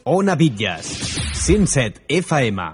bac78f910e5c65c550db21c5318def3f7fab6a8f.mp3 Títol Ona Bitlles Emissora Ona Bitlles Titularitat Pública municipal Descripció Indicatiu de l'emissora al 107.0 FM.